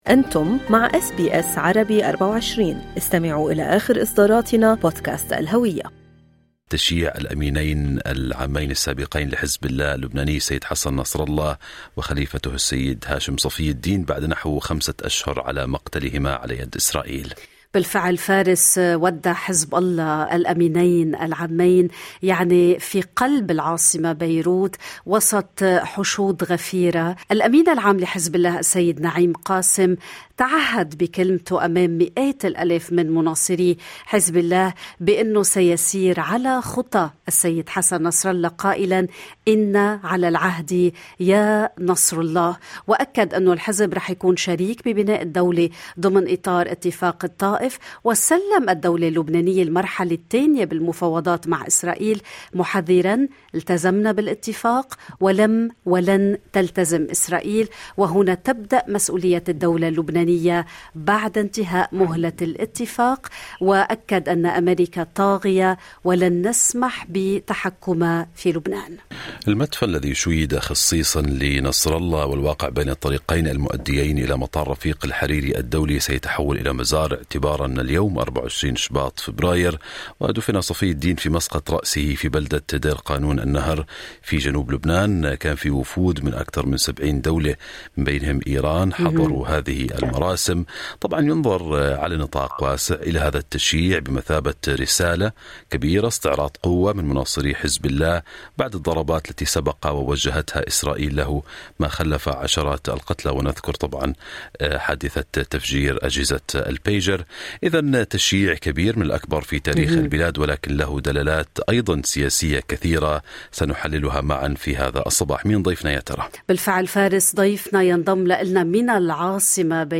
الذي ينضم الينا من العاصمة بيروت